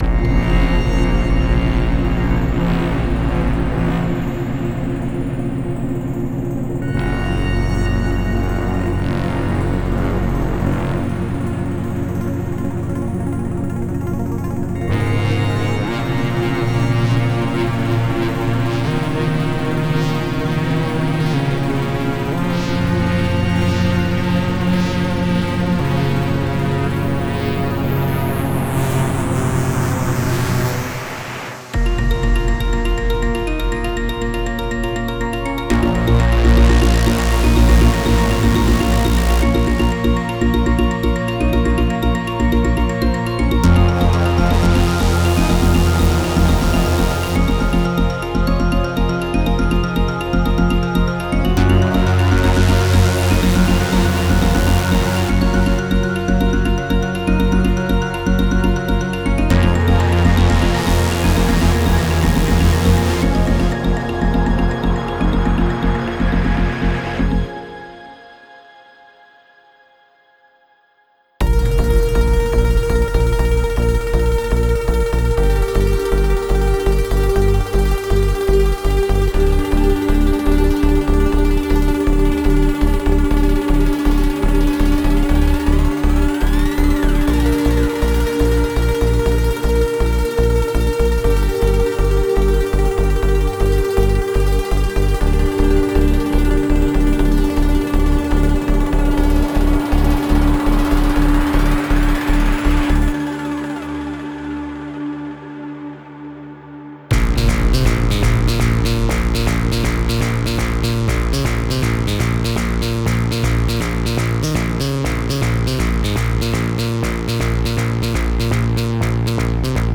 Melodic Techno Trance
It combines elements from Ambent, Trance, Techno.
Starwave will help you create a sound that merges energy, mystery, and the boundless cosmos.